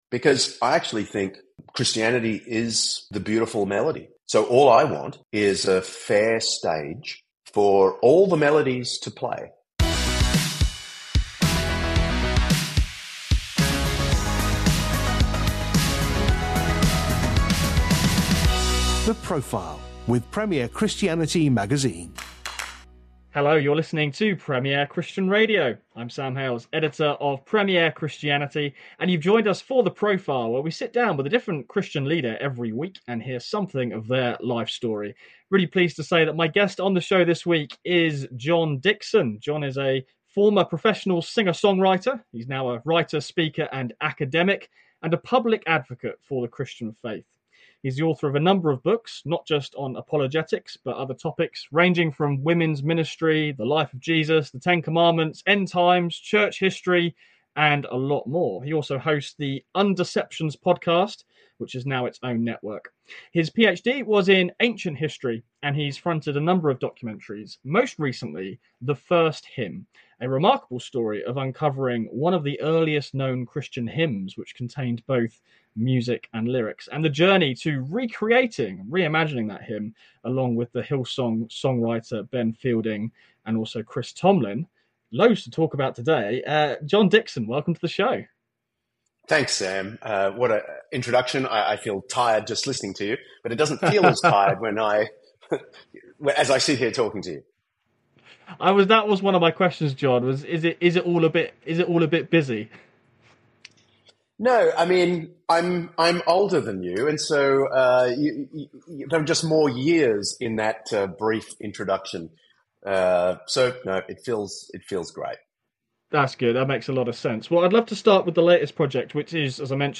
An Empire Podcast Interview Special 50:02
An Empire Podcast Interview Special 50:02 Play Pause 6h ago 50:02 Play Pause Play later Play later Lists Like Liked 50:02 Every year at the London Film Festival, Empire hosts a gala screening for a film that we particularly admire.